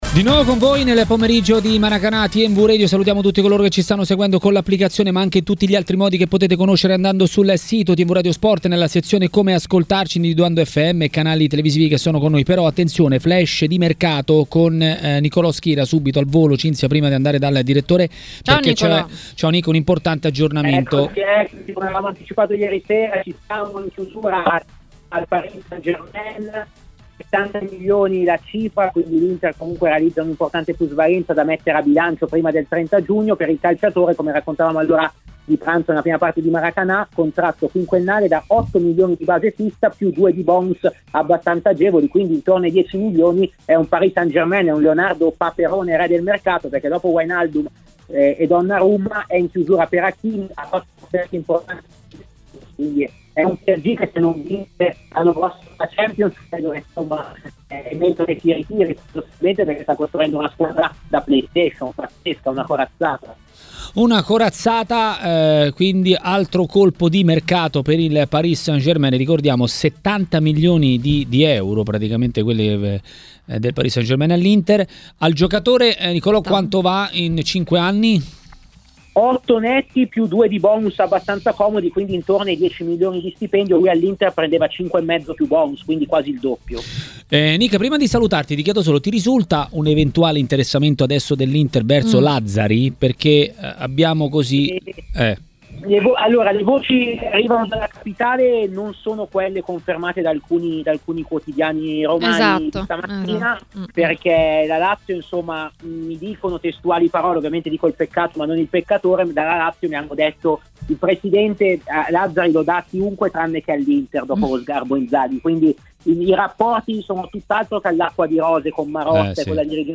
Per parlare di Euro 2020 ma anche di mercato a Maracanà, nel pomeriggio di TMW Radio, è intervenuto il direttore Mario Sconcerti.